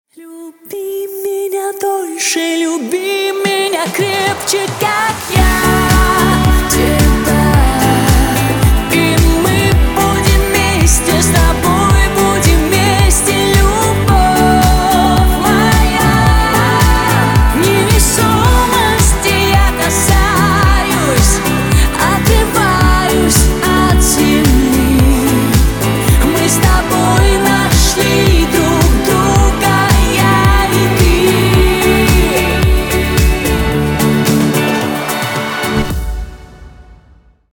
• Качество: 192, Stereo
поп
женский вокал
лирика